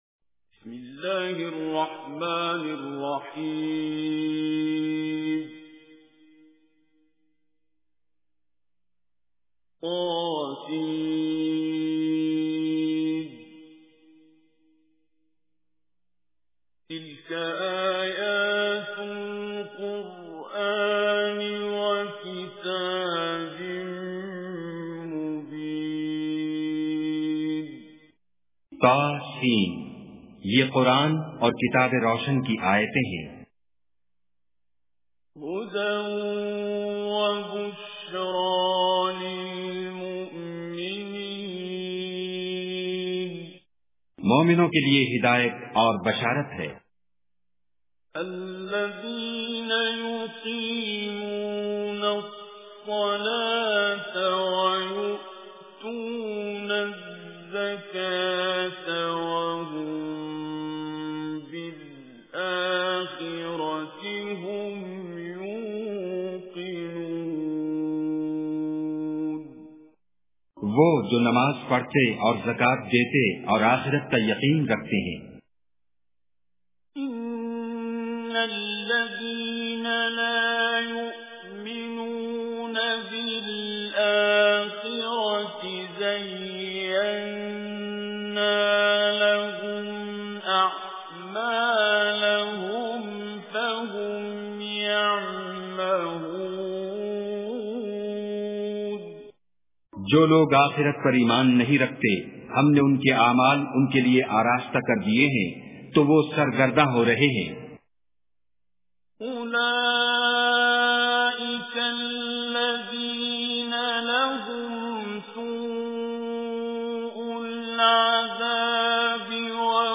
Surah An-Naml With Urdu Translation By Qari Basit
Listen online and download beautiful recitation of Surah An Naml.